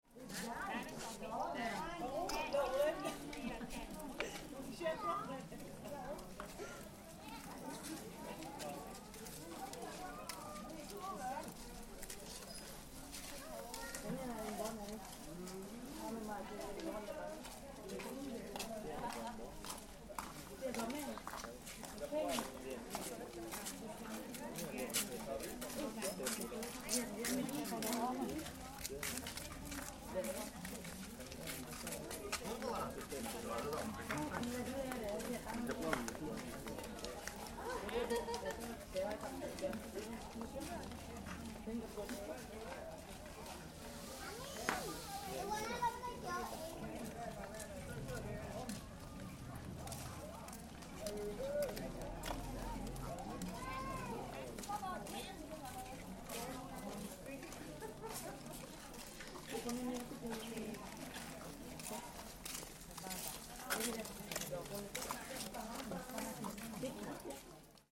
A night market in Koh Phangan, an island off the coast of Thailand - a soundwalk through the busy market, with all the bustle and noise of stallholders and customers.